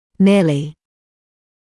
[‘nɪəlɪ][‘ниэли]почти, приблизительно